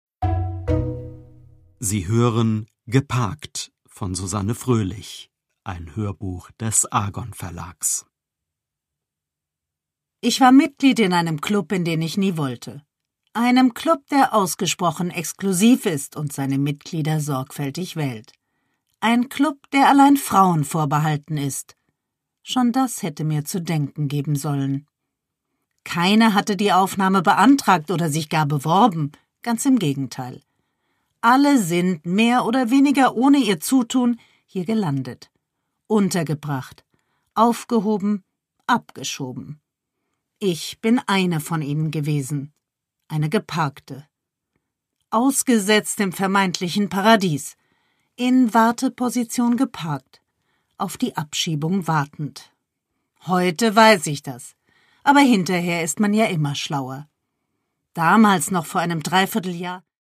Produkttyp: Hörbuch-Download
Gelesen von: Susanne Fröhlich